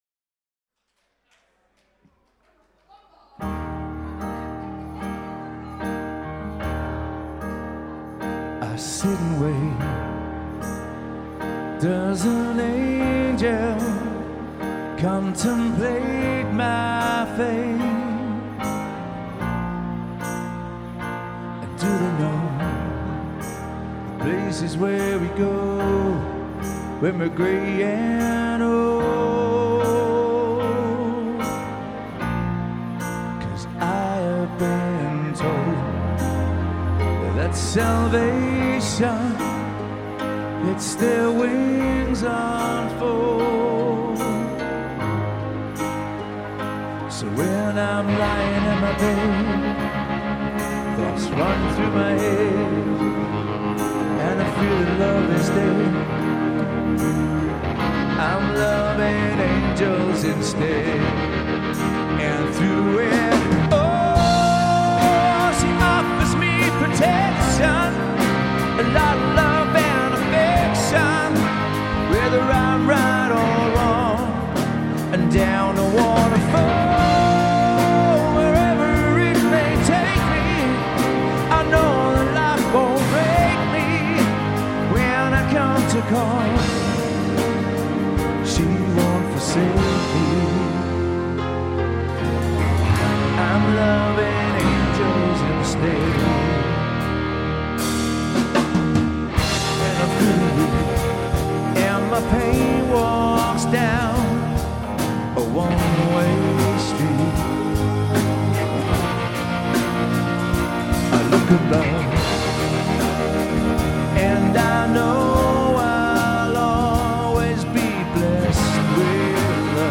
Indie Wedding Band | Rock Wedding Band, Function Band
4-piece wedding band